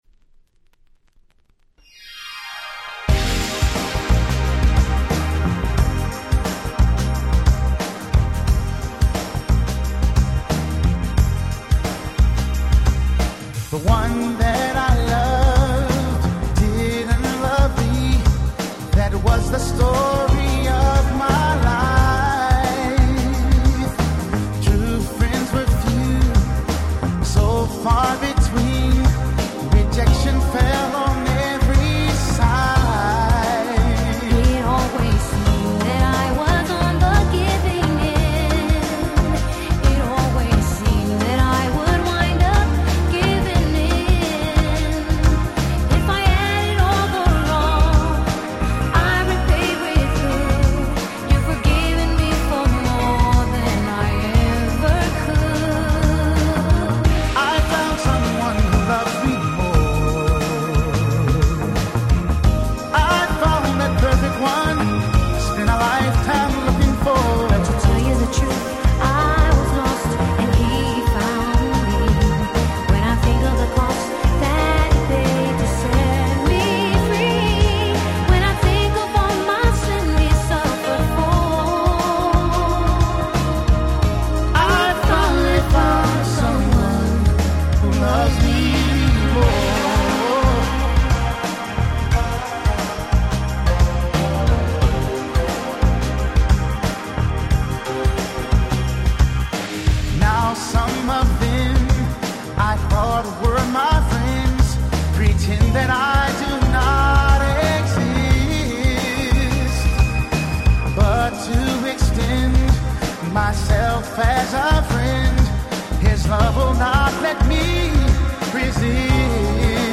97' Nice UK Soul/Ground Beat !!
ゆったりとしたGround Beat調のトラックに超絶ムーディーなデュエットが載る最高のバラード。